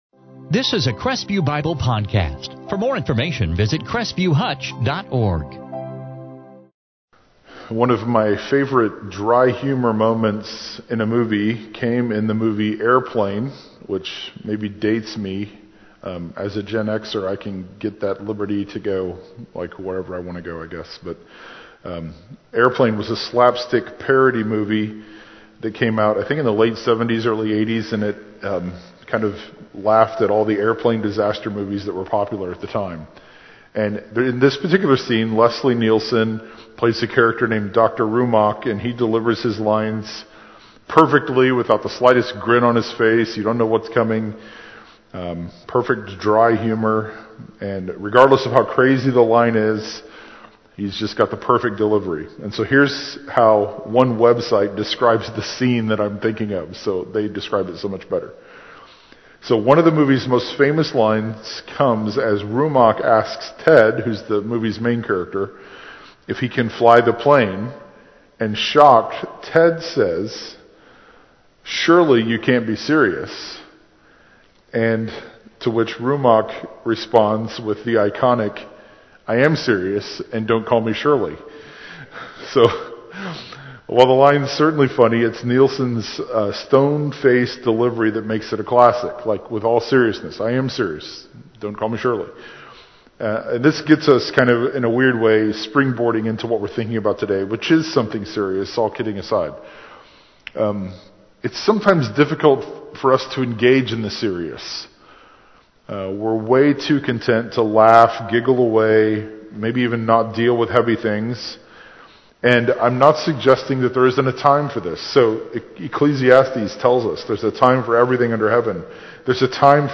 Transcript In this sermon from 2 Samuel 21